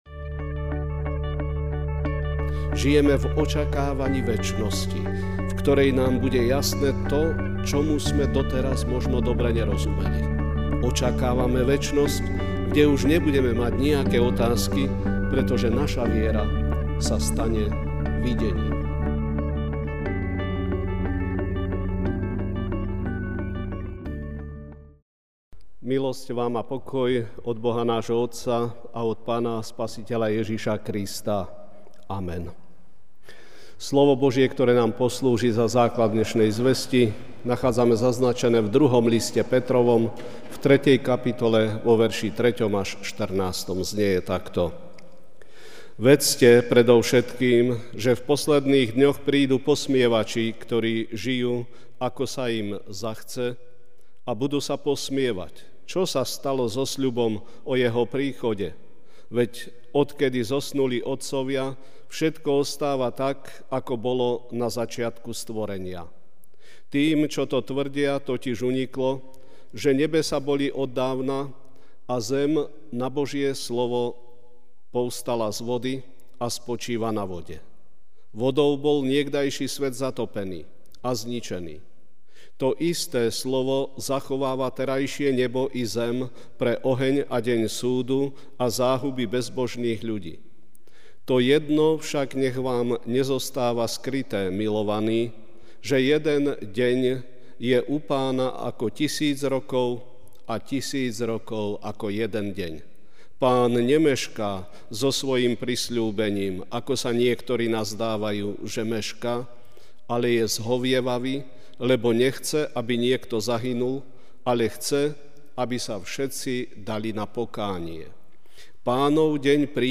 Večerná kázeň: Očakávanie ( 2 Pt 3: 3-14) Predovšetkým vedzte, že posmievači, žijúci podľa svojich žiadostí, prídu v posledné dni s výsmechom a povedia: Čože je so sľubom o Jeho príchode?